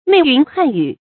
袂云汗雨 注音： ㄇㄟˋ ㄧㄨㄣˊ ㄏㄢˋ ㄧㄩˇ 讀音讀法： 意思解釋： 形容行人之多。